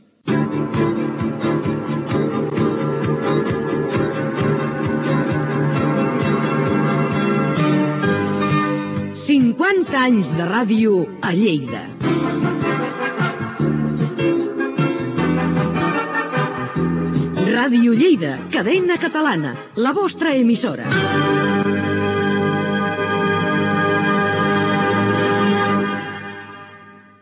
Indicatiu dels 50 anys de l'emissora